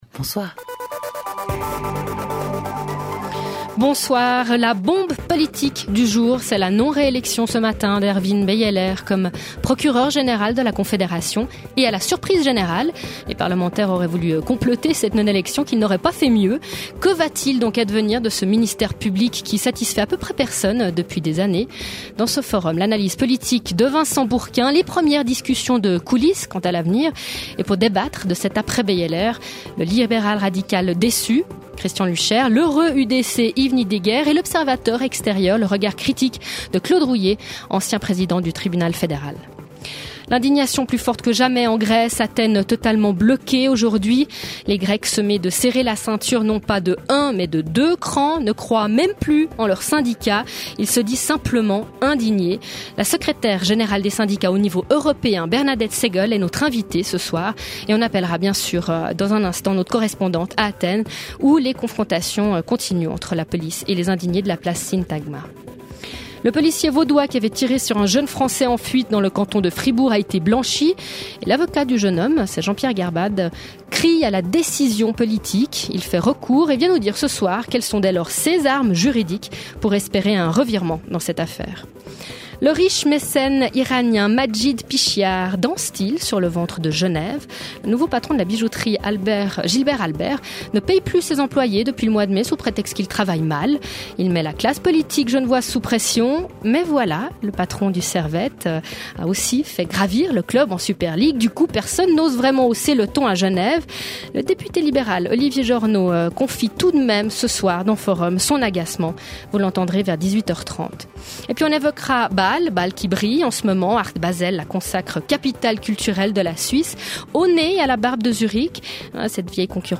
7 jours sur 7, Forum questionne en direct les acteurs de l’actualité, ouvre le débat sur les controverses qui animent la vie politique, culturelle et économique.